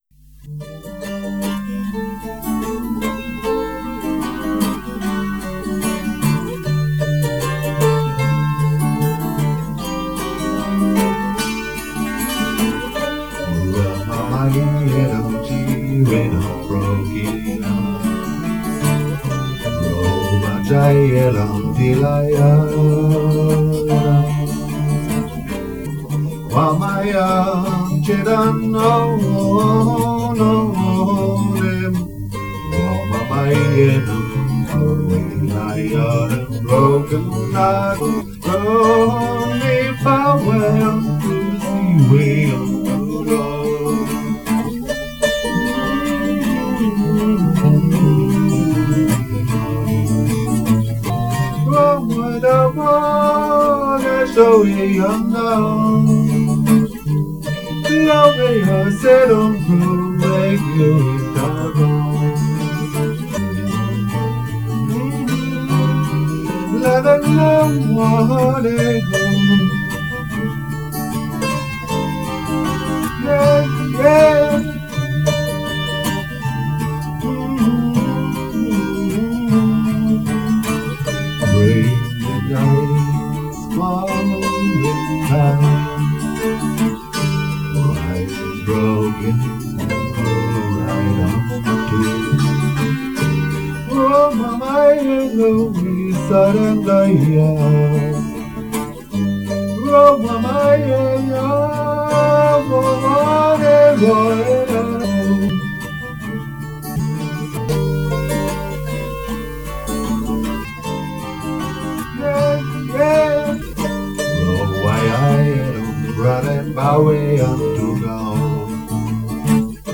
Session draußen im Wind
Santur
Bass
Gitarre + Vocal